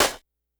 Snares
snr_50.wav